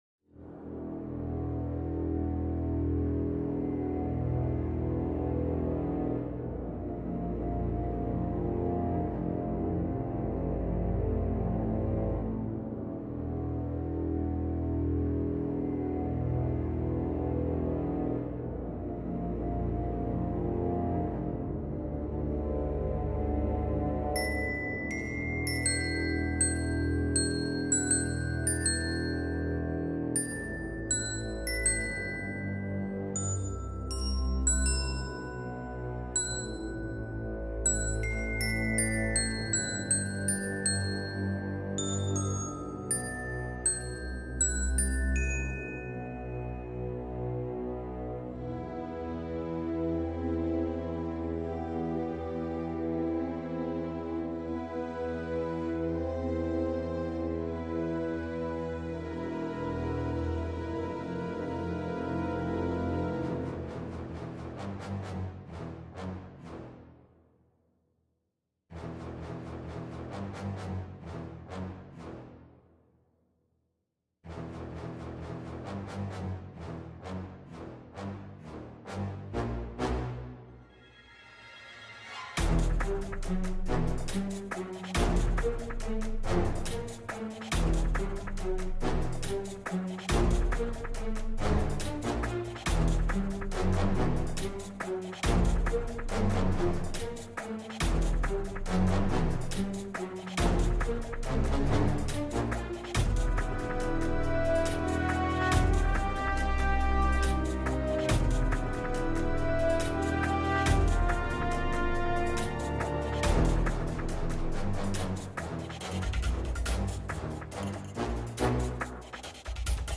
pony orchestrated theme